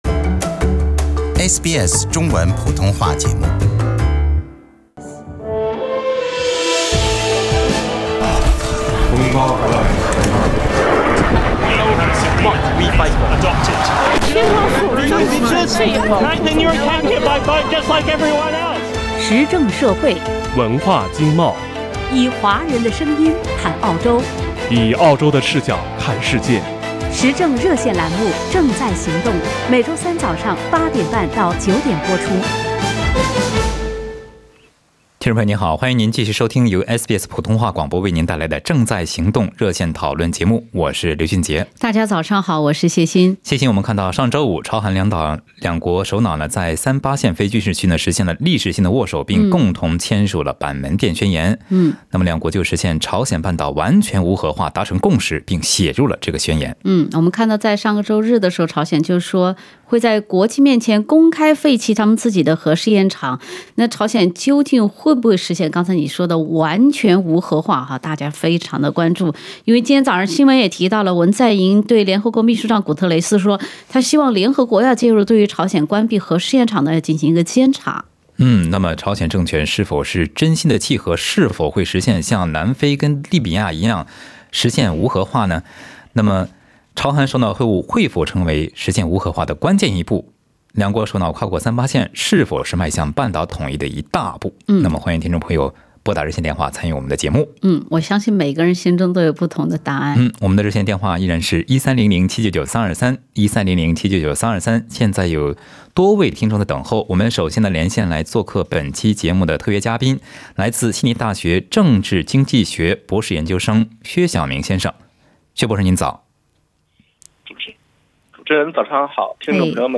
另外，有听众打入电话表达了他们的看法，以下为部分听众观点：